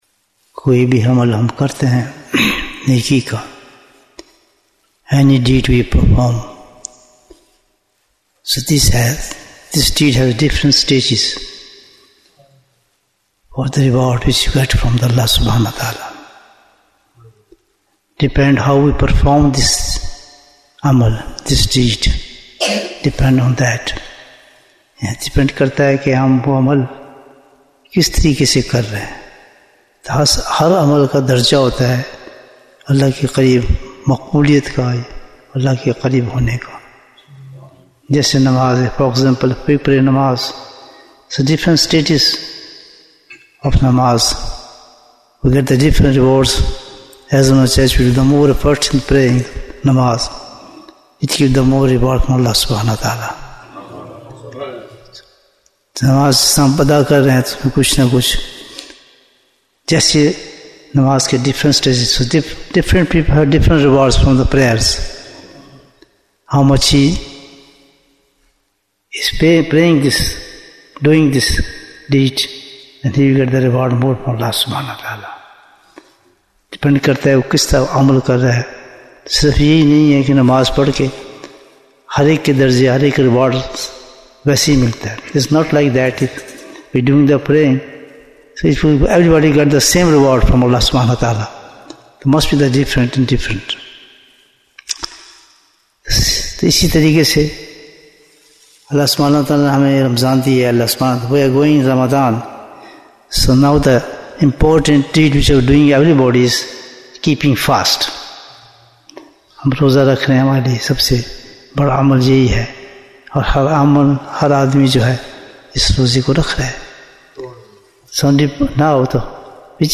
Bayan, 9 minutes